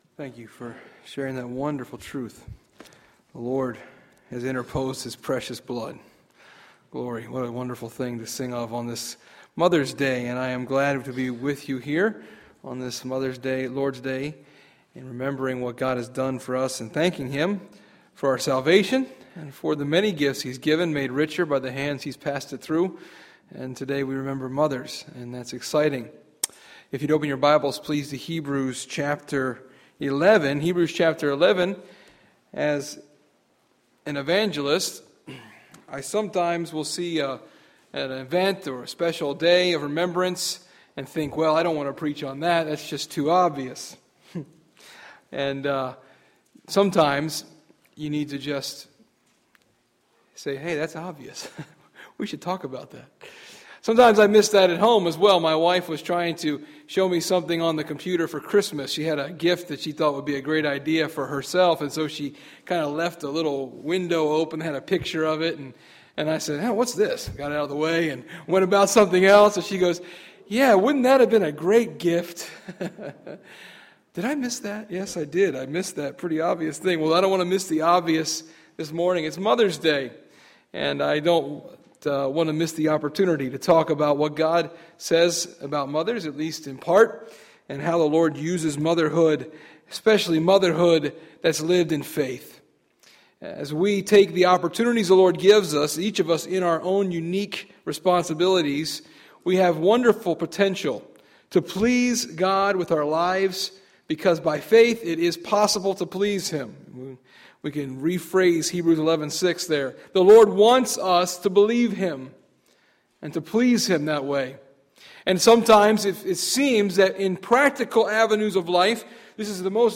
Sunday, May 13, 2012 – Morning Message